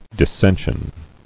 [dis·sen·sion]